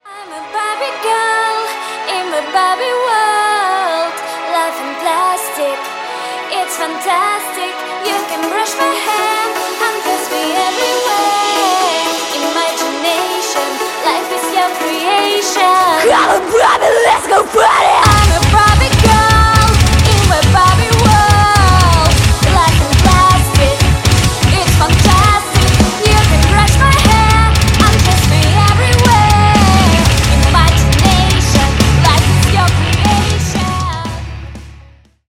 Рок Металл
громкие # кавер